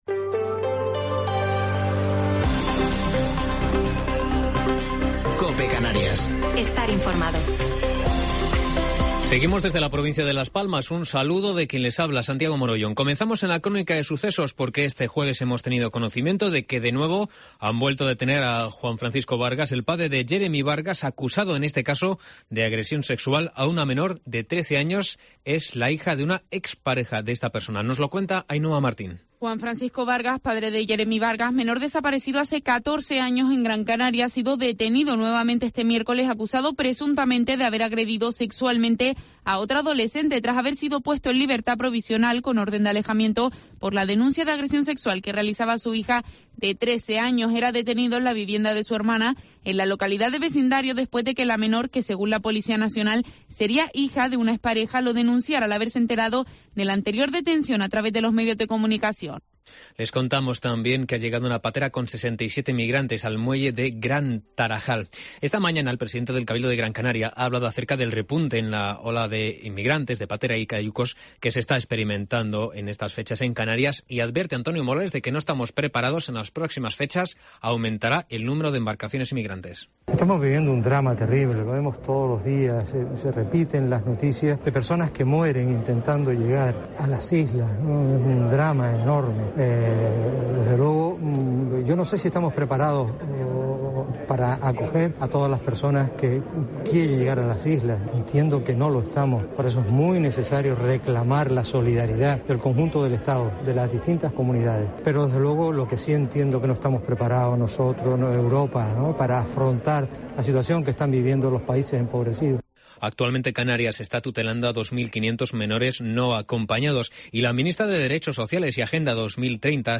Informativo local x2 de septiembre de 2021